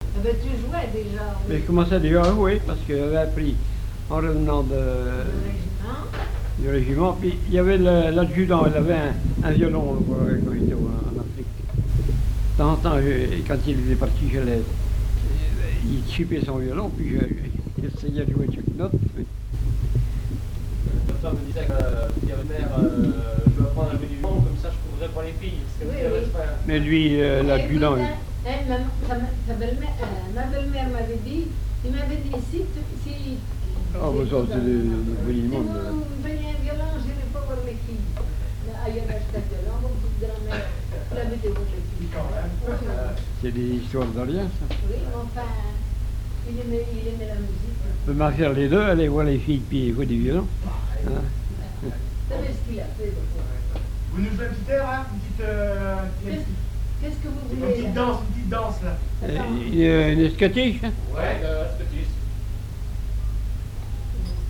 violoneux, violon
musique traditionnelle
Témoignages sur le violoneux, airs à danser, chansons et un monologue